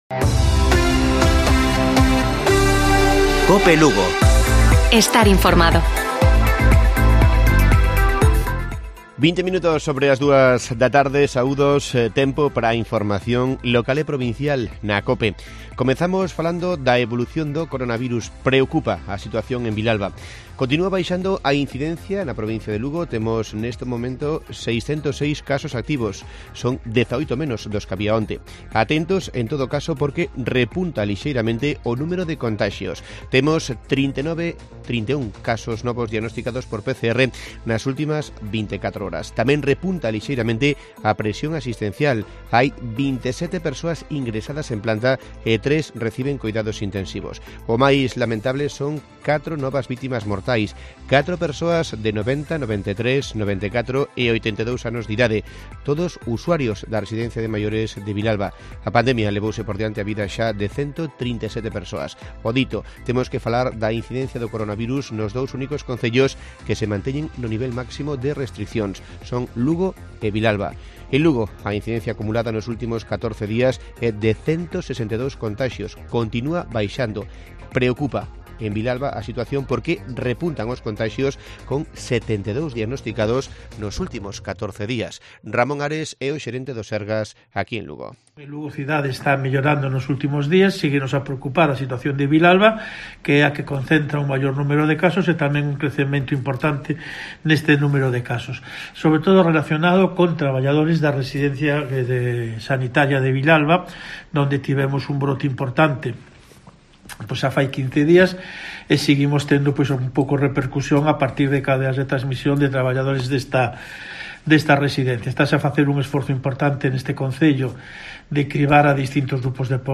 Informativo Mediodía de Cope Lugo. 09 de diciembre. 14:20 horas